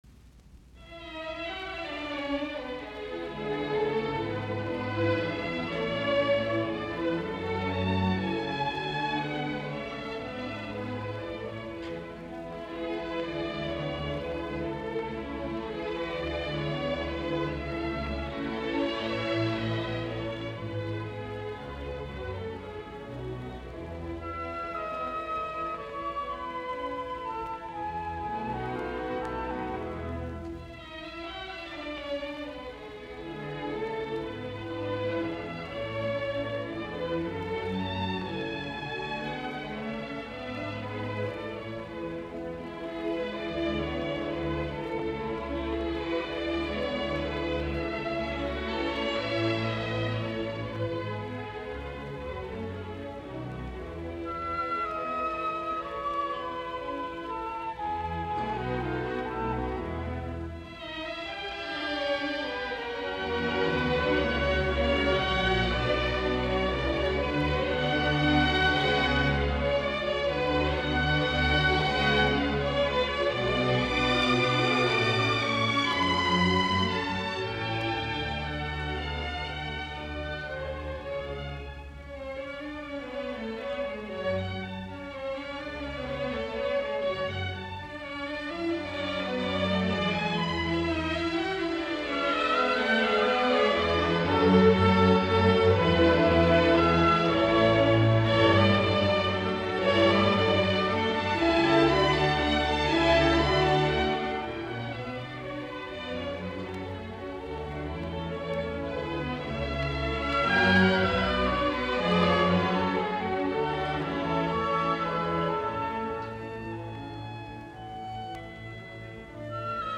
Taltioitu radioidusta esityksestä 28.2.1954.